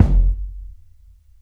KICK 1.wav